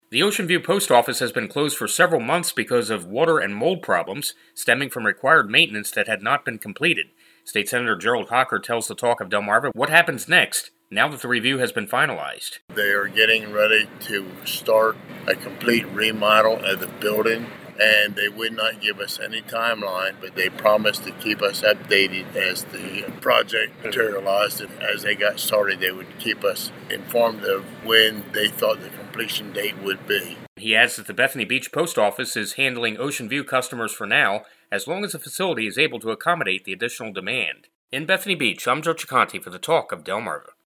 Here is the full update and explanation of the situation from State Senator Gerald Hocker…